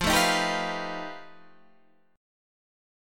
F9b5 chord